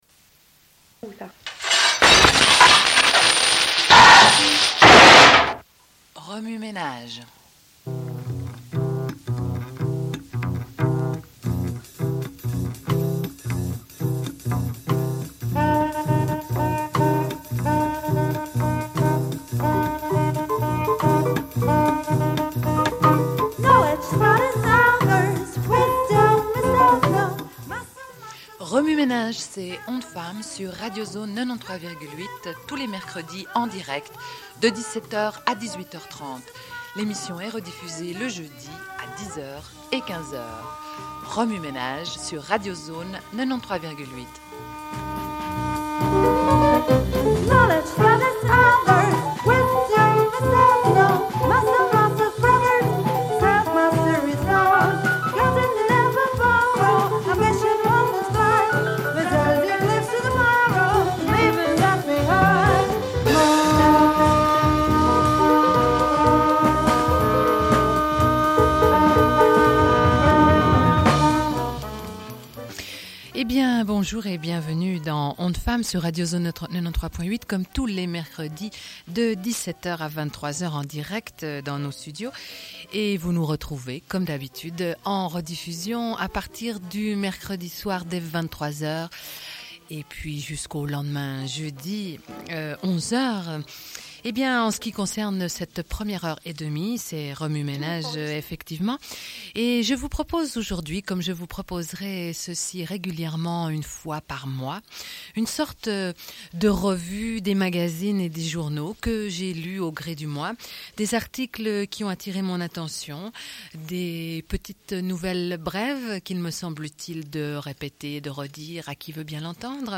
Une cassette audio, face A31:24